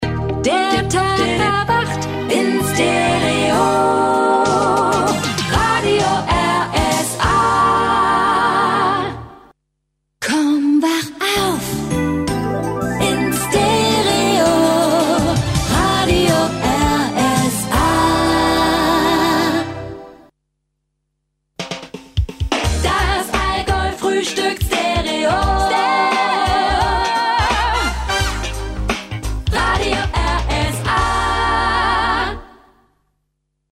deutsche Sprecherin für Werbung, Dokumentationen, Voice Over, TV, Radio uvm.
Kein Dialekt
Sprechprobe: Sonstiges (Muttersprache):
female german voice over artist